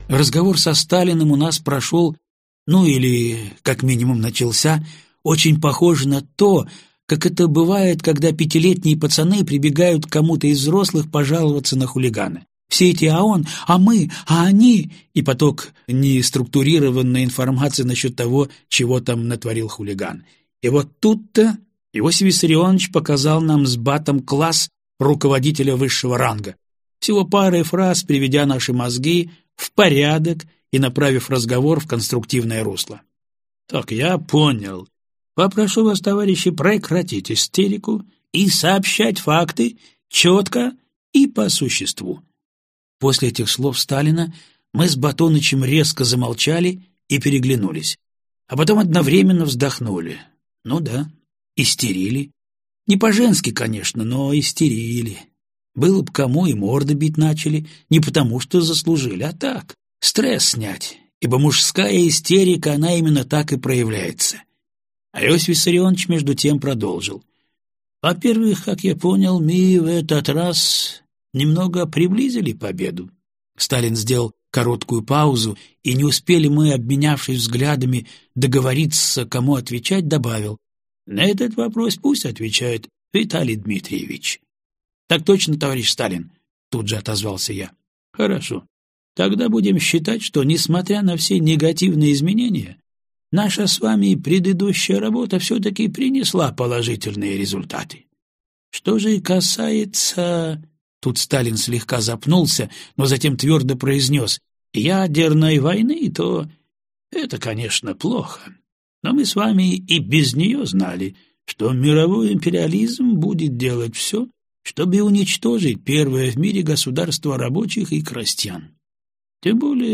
Аудиокнига Дорога к Вождю | Библиотека аудиокниг